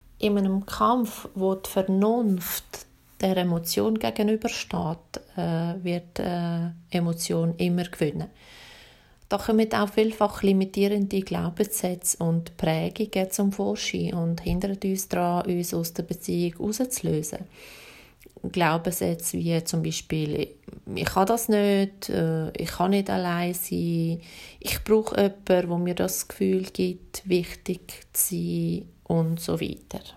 Dieses Interview gibt es auch auf HOCHDEUTSCH!!!